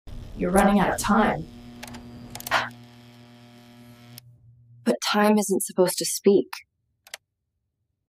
Eerie Futuristic ASMR That’s Oddly Satisfying